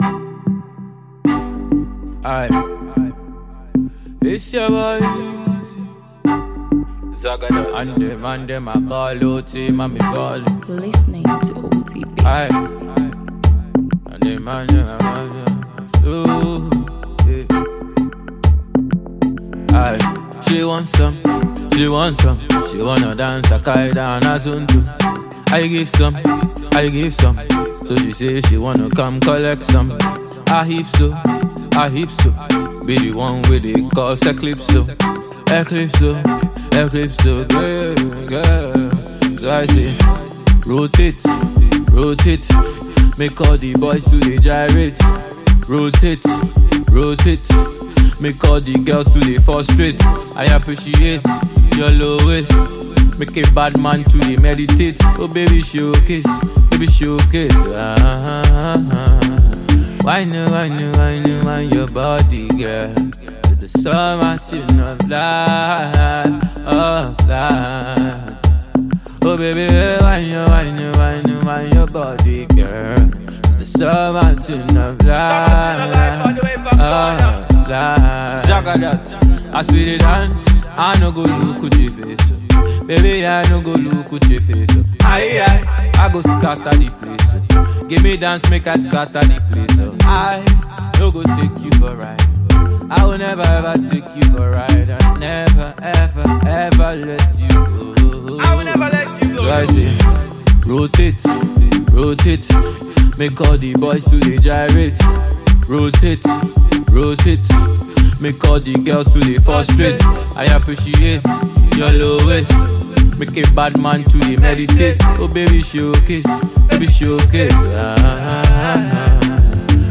a freestyle.